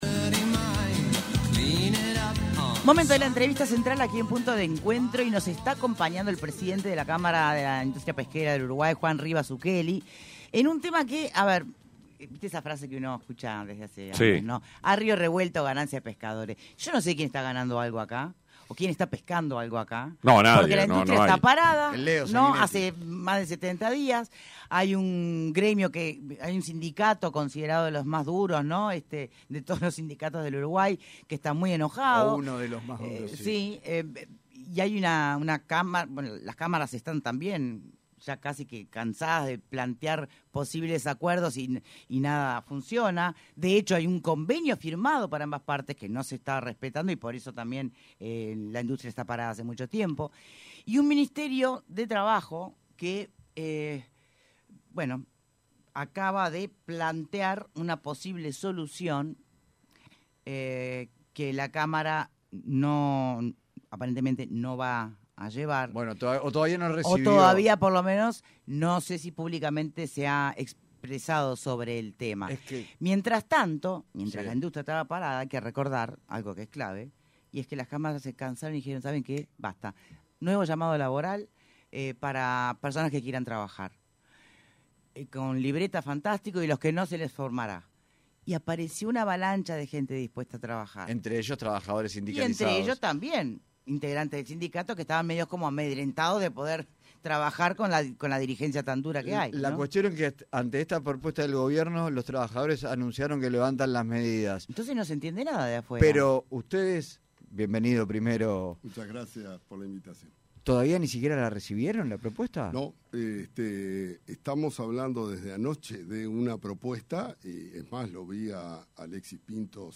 entrevista con Punto de Encuentro